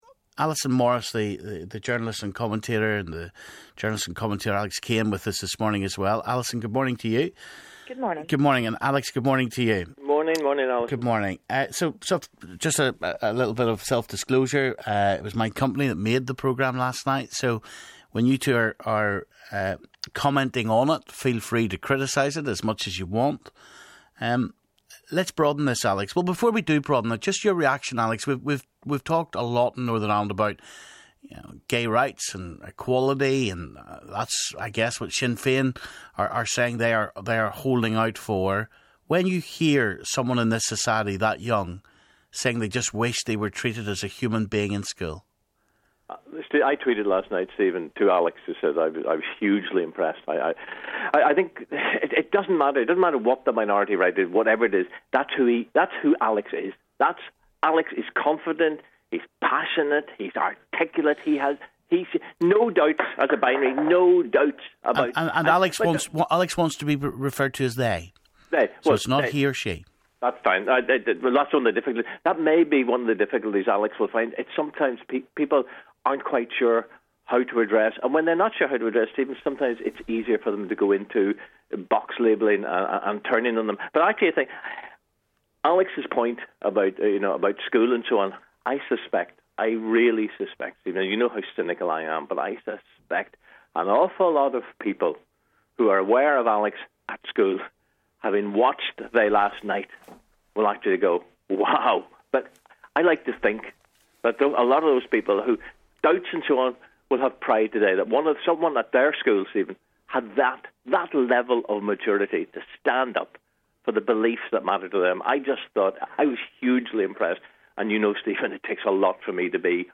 Political commentators